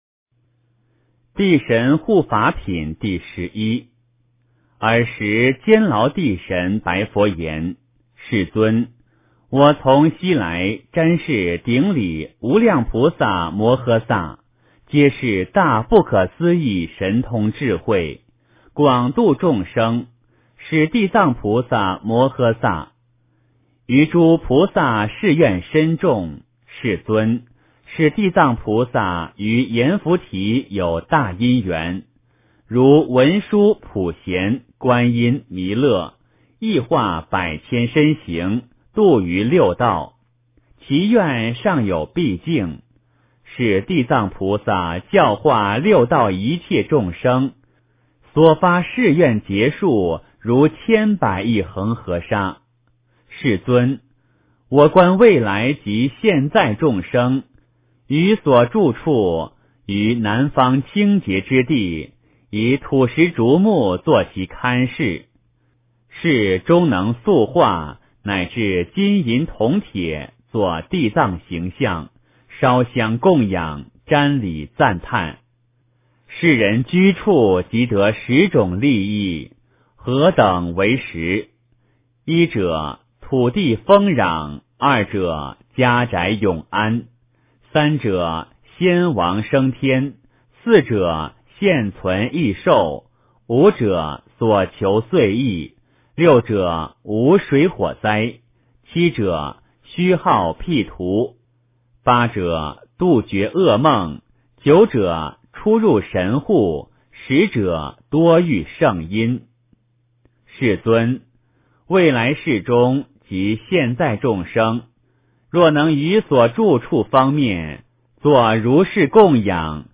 地藏经-地神护法品第十一 诵经 地藏经-地神护法品第十一--佛经 点我： 标签: 佛音 诵经 佛教音乐 返回列表 上一篇： 地藏经-称佛名号品第九 下一篇： 地藏经-嘱累人天品第十三 相关文章 《文殊菩萨传》开窍：逃学反而契入智慧王国 《文殊菩萨传》开窍：逃学反而契入智慧王国--佚名...